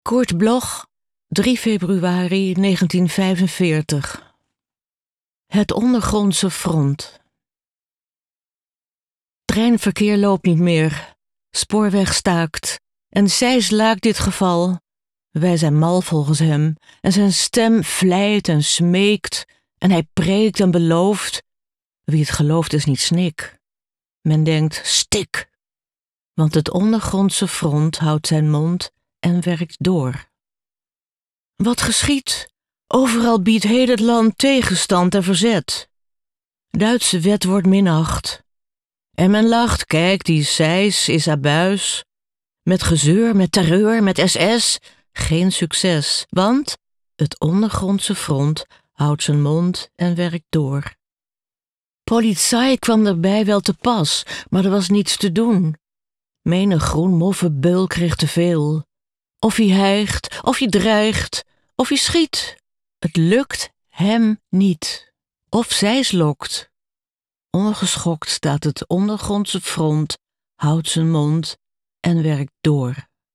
Aufnahme: Studio Levalo, Amsterdam · Bearbeitung: Kristen & Schmidt, Wiesbaden